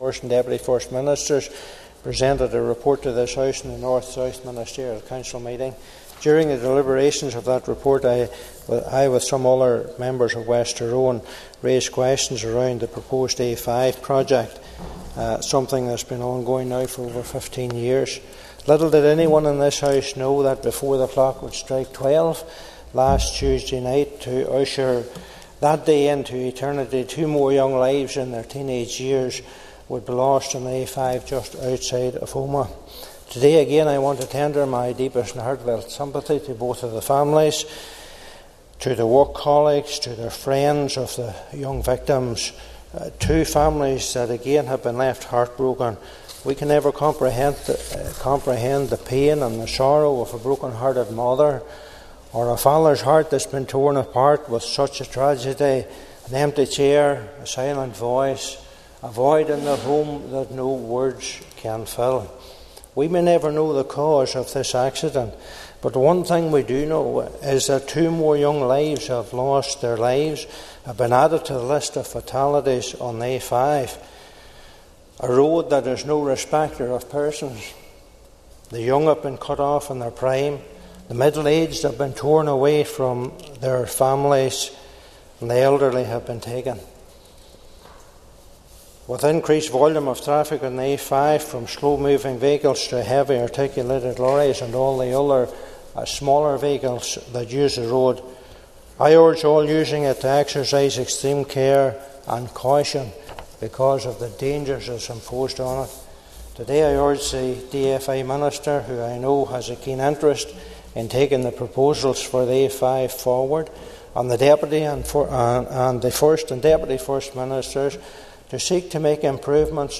The Stormont Assembly has heard tributes to two teens who died on the A5 on Tuesday of last week, with DUP MLA Tom Buchanan reminding members that earlier on the day of the tragedy, the assembly had been discussing safety on the road in the context of the North South Ministerial Council.